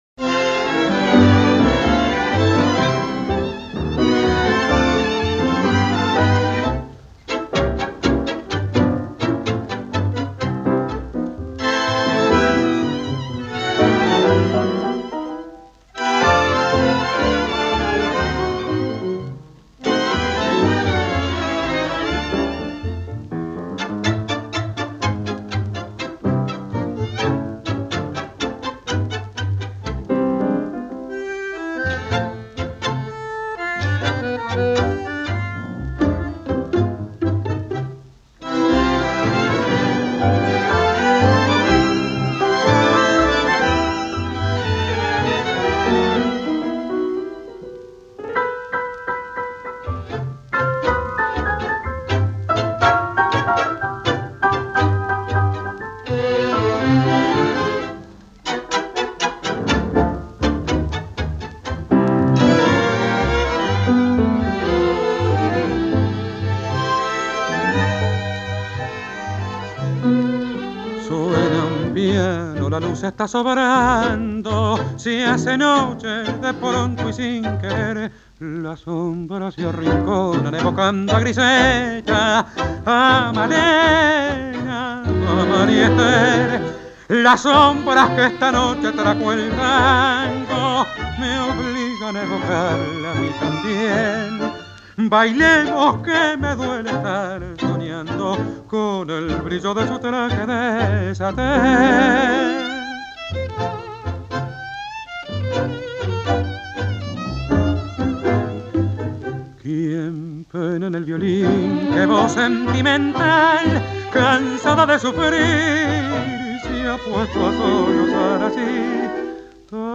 tangos